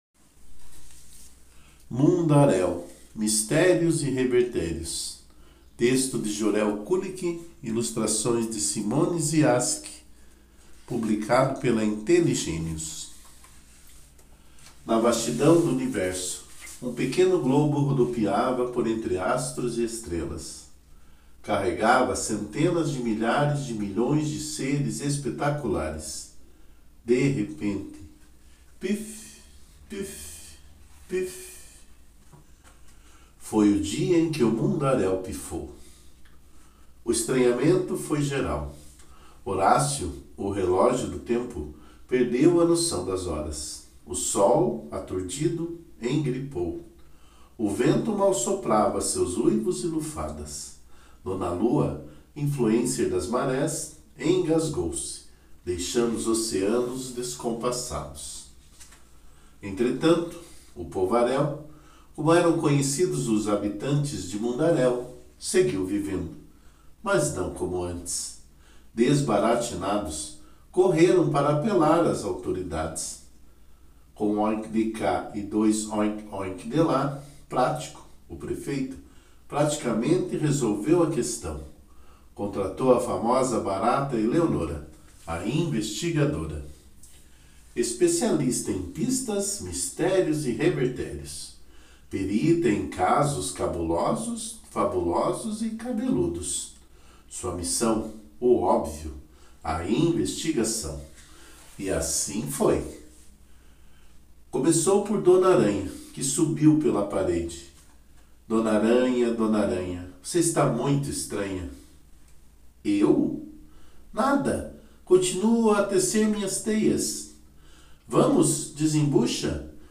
Leitura Guiada